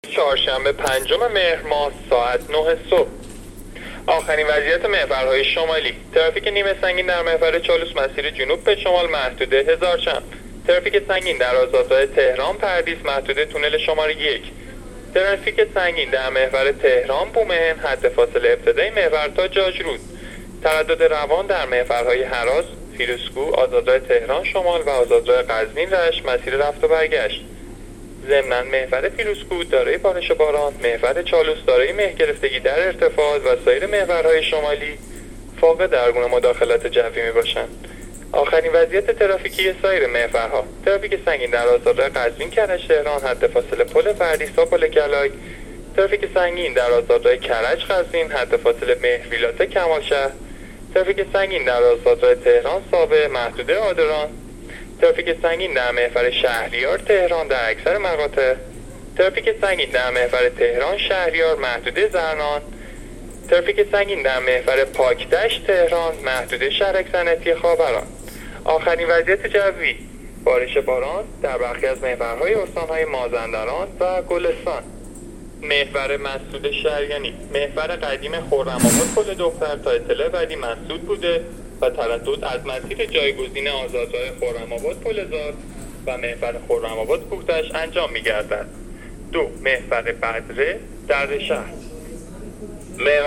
گزارش رادیو اینترنتی از آخرین وضعیت ترافیکی جاده‌ها تا ساعت ۹ پنجم مهر؛